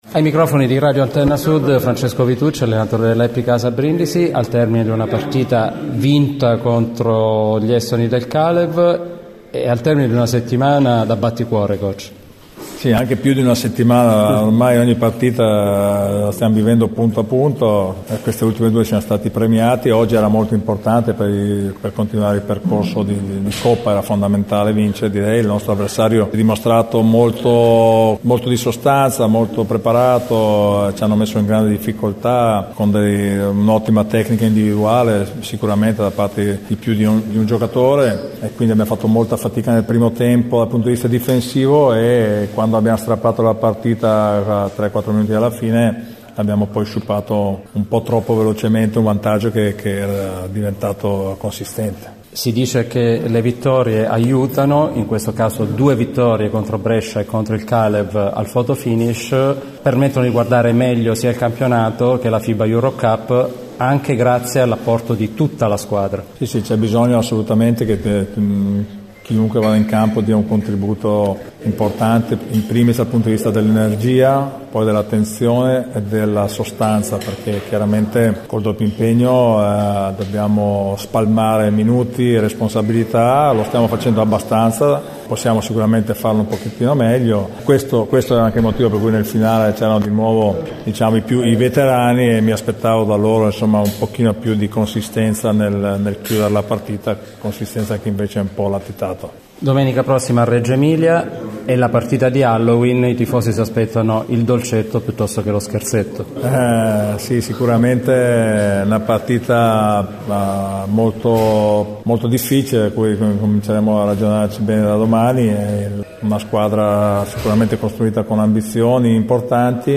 Interviste